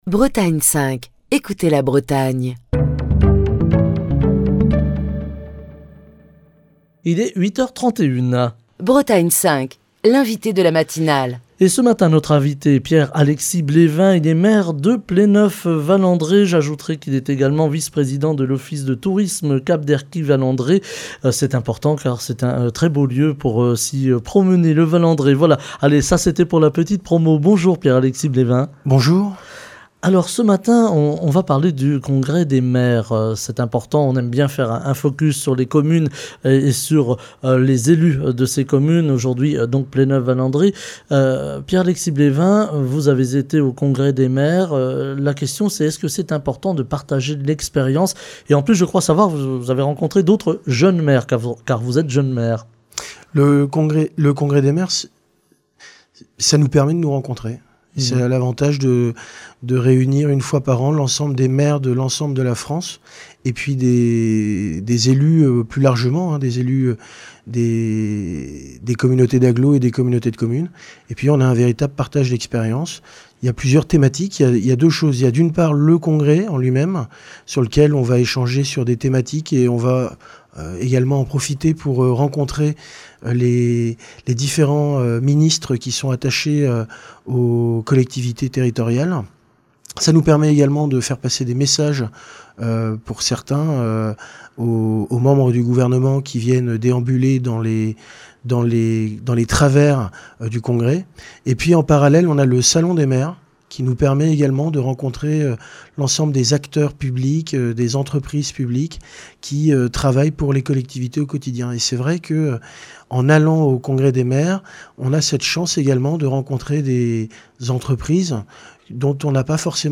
Les maires, qui font également face à l'augmentation des coûts de l'énergie qui impacte le fonctionnement de leur municipalité, doivent réaliser des économies pour mener à bien les projets d'aménagements. Ce matin, l'exemple de Pléneuf-Val-andré avec Pierre-Alexis Blévin, maire de Pléneuf-Val-André, qui est l'invité de la matinale.